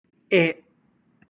/e/